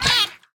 sounds / mob / dolphin / hurt2.ogg